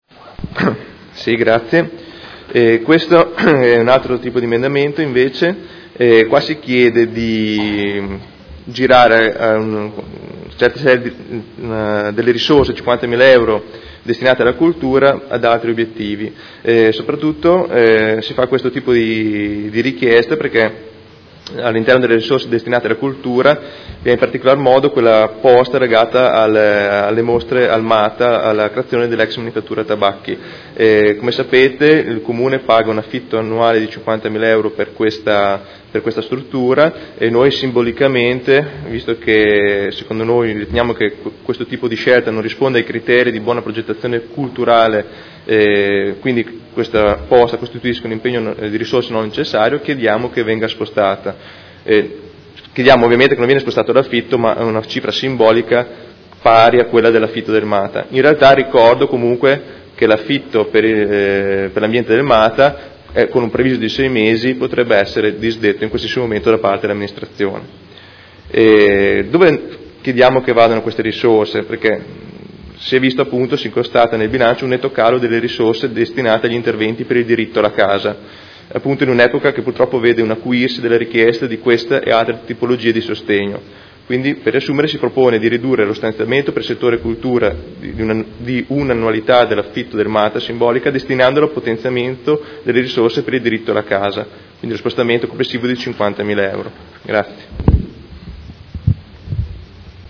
Seduta del 26 gennaio. Bilancio preventivo: emendamento n°9676